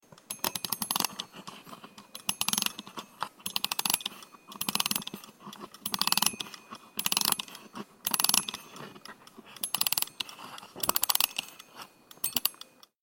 دانلود صدای کوک کردن ساعت یا اسباب بازی از ساعد نیوز با لینک مستقیم و کیفیت بالا
جلوه های صوتی
برچسب: دانلود آهنگ های افکت صوتی اشیاء